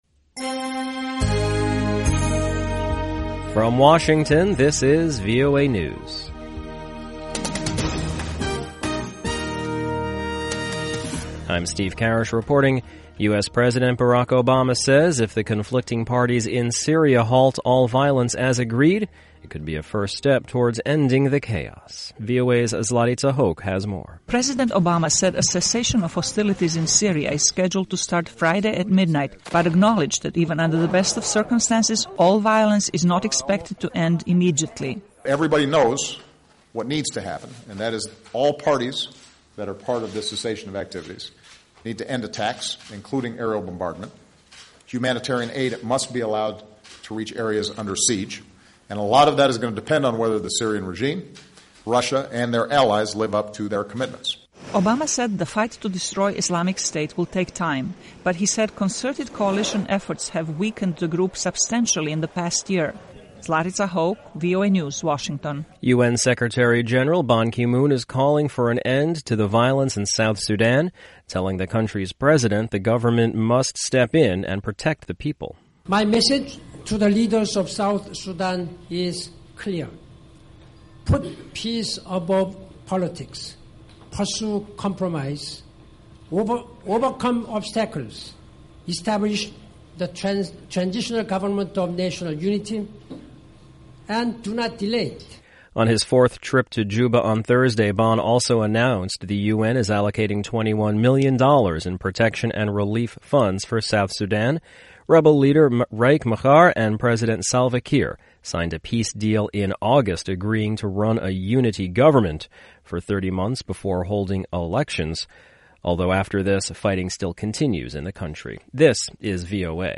VOA English Newscast 1500 UTC February 26, 2016